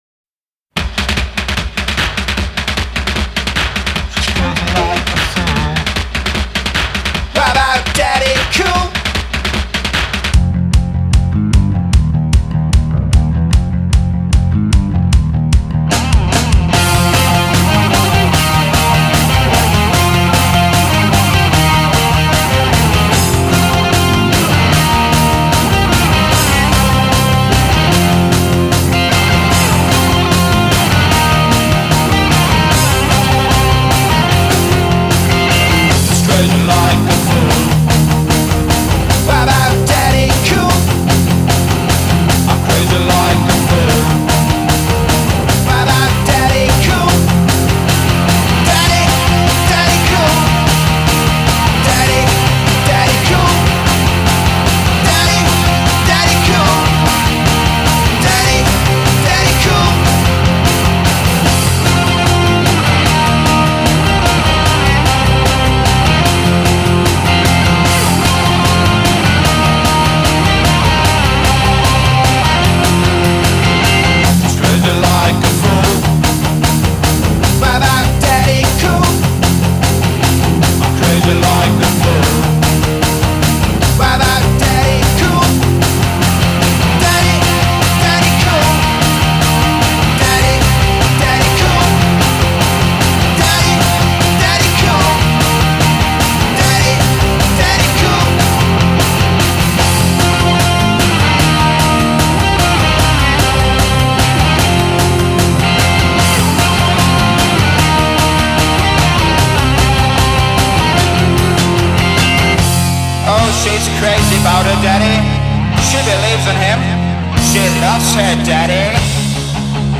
une reprise punk